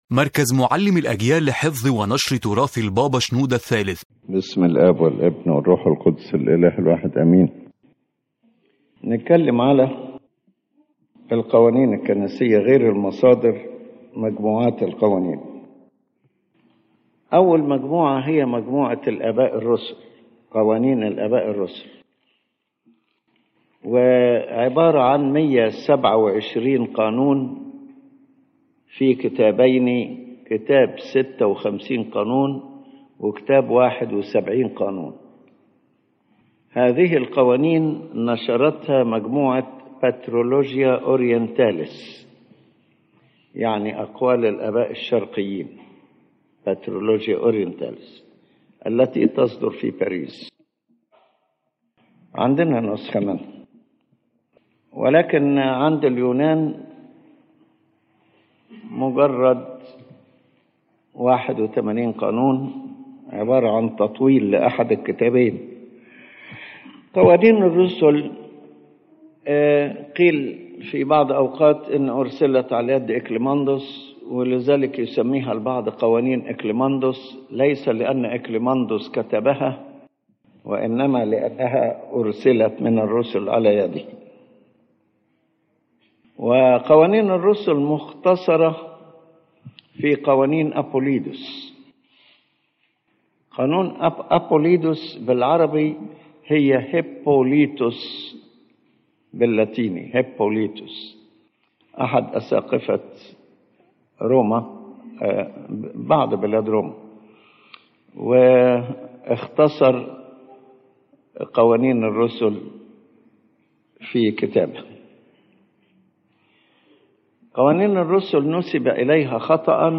This lecture explains the sources and collections of church canon laws in the Coptic Orthodox Church, clarifying their historical development, distinguishing authentic laws from falsely attributed ones, and highlighting the role of ecumenical councils, local councils, and the teachings of the Fathers in shaping canonical thought.